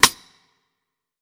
Air Rim.wav